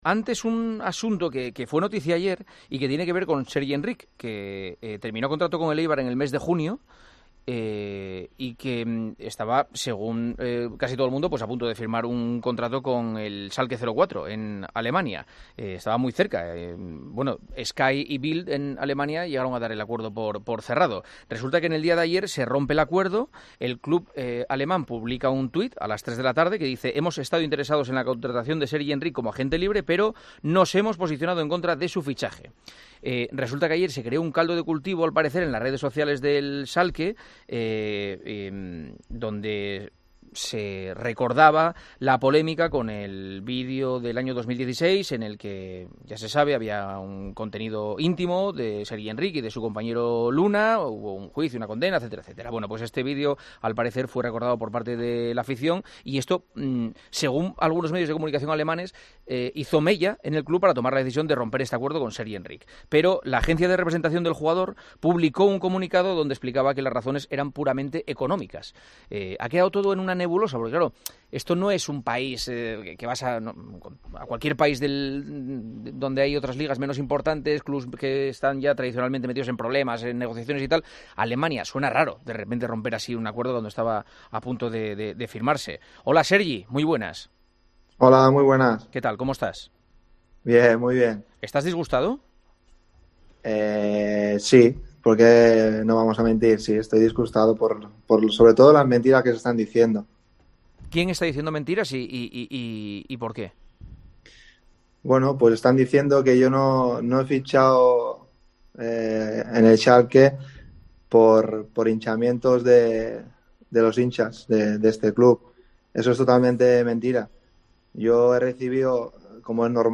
El futbolista español explicó en El Partidazo de COPE los hechos acontecidos que han echado para atrás finalmente su fichaje por el Schalke 04 alemán.